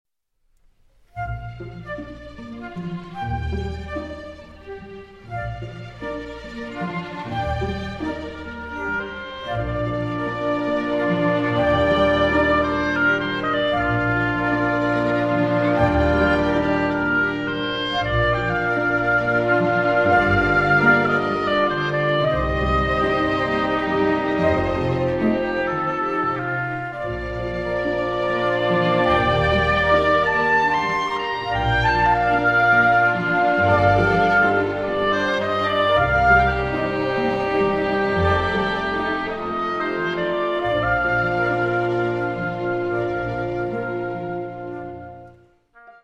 oboe
for solo oboe and small orchestra
Poco adagio (swelling like the sea)
Recorded in the Presence of the Composer